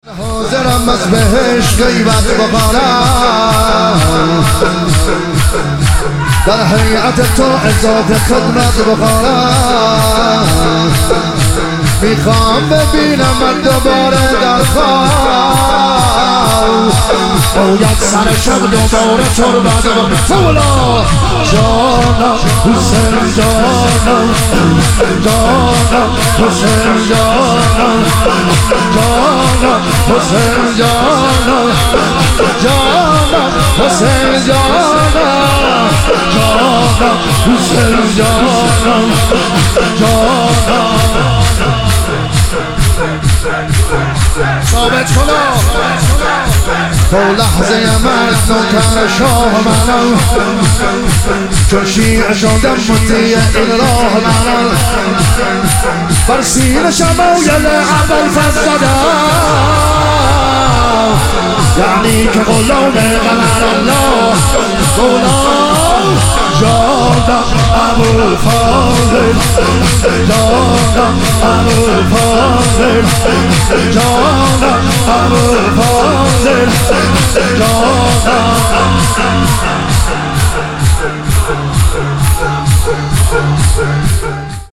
مشهد الرضا - شور